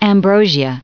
added pronounciation and merriam webster audio
44_ambrosial.ogg